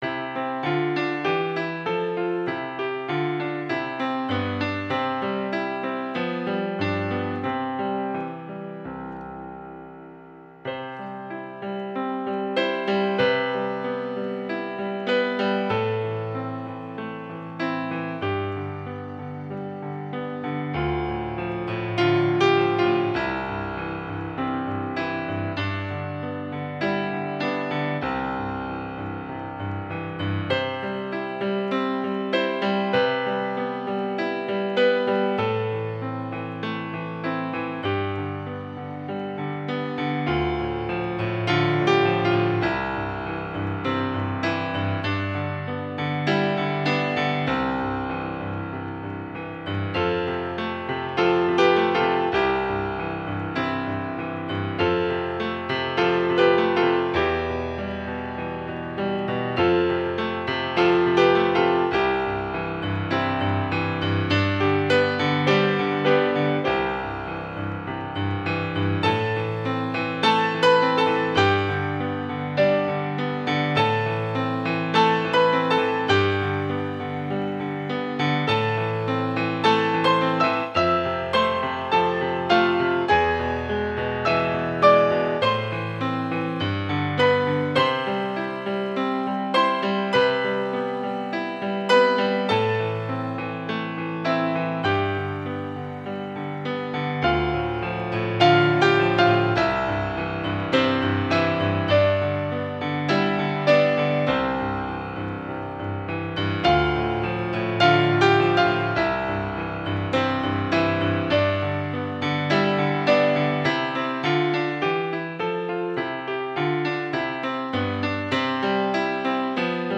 Original Piano / Keyboard